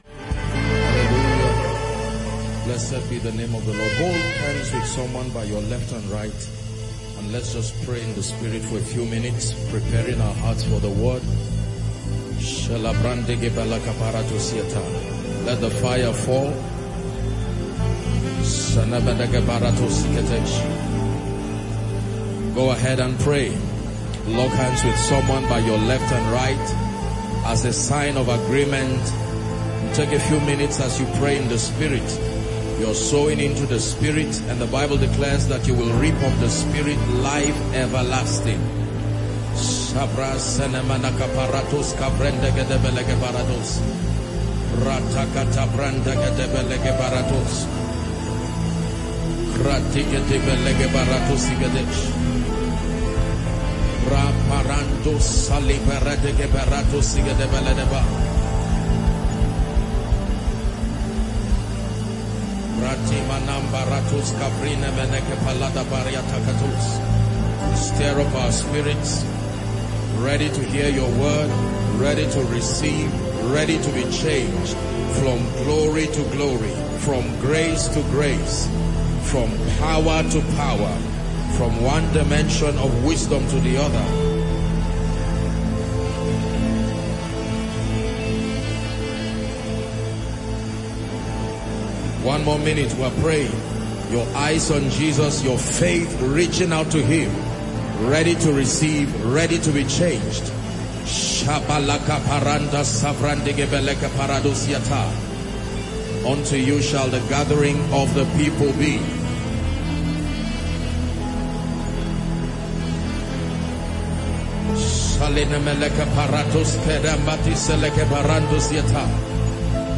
This teaching series serves as a foundational guide for believers seeking to build a solid understanding of the Christian faith. The first part of the series explored key biblical principles, emphasizing the importance of sound doctrine, spiritual maturity, and unwavering faith in God.